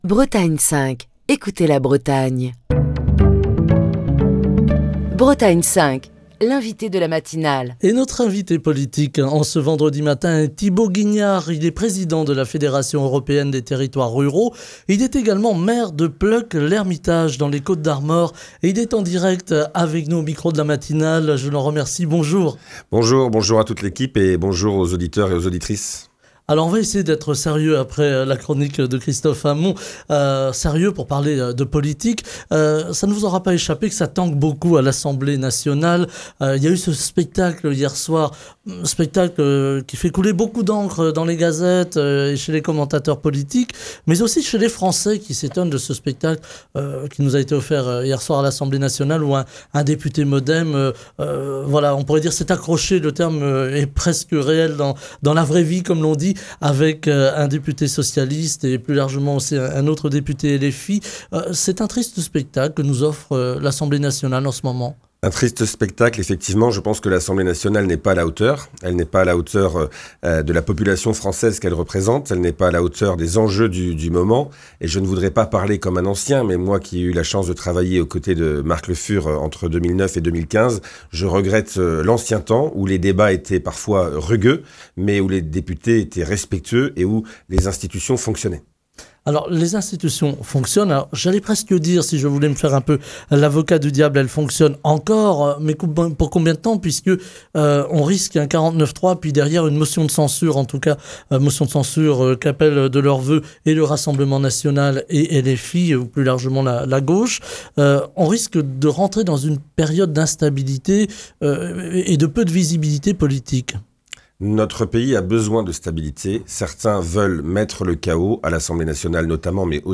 Thibaut Guignard, président de la Fédération européenne des territoires ruraux et maire de Plœuc-L’Hermitage dans les Côtes-d'Armor, est l'invité de la matinale de Bretagne 5 ce vendredi pour commenter l'actualité politique. Il exprime son indignation face au chaos qui a régné à l'Assemblée nationale, où une altercation virulente a éclaté hier soir entre le député MoDem Nicolas Turquois et le député socialiste Michael Bouloux.